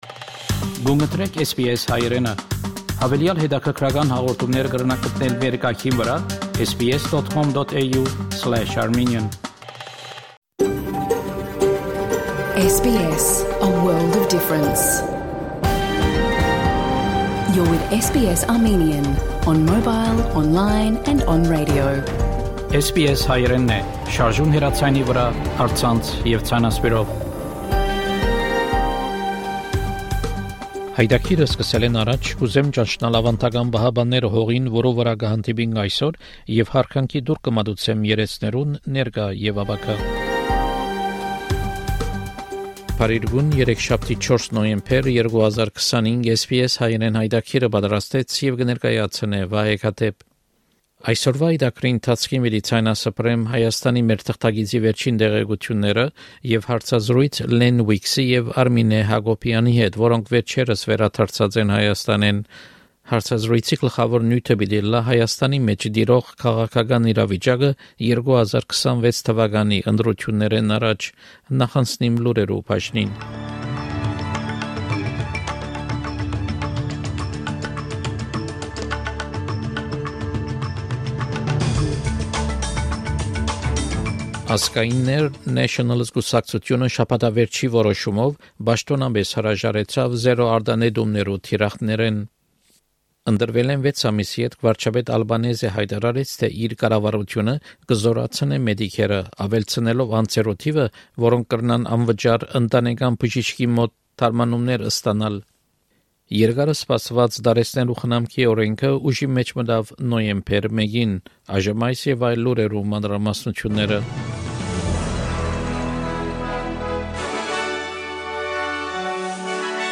SBS Armenian news bulletin from 4 November 2025 program.